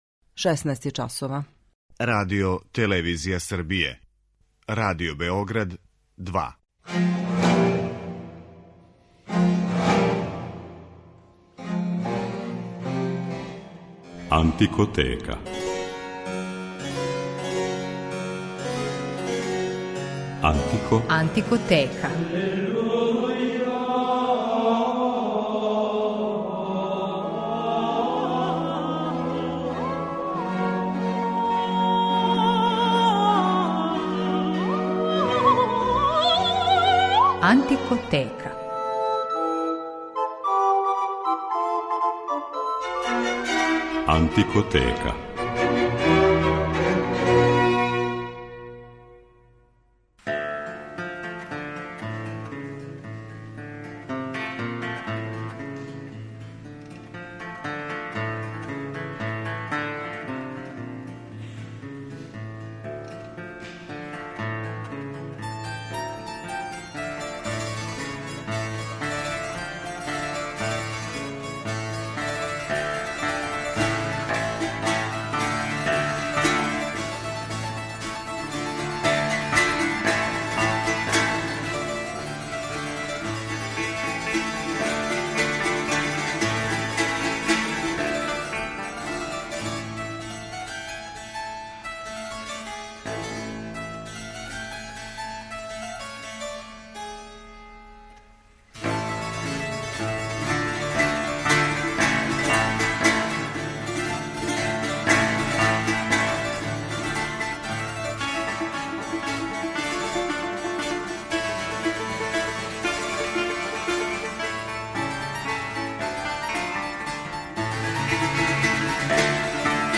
Шпанска музика барока богата је плесним ритмовима, који су били извор инспирације многим композиторима да их уметнички обликују. Данашња емисија посвећена је играма шпанског 18. века, које осликавају универзалност и колонијални дух земље и њене културе.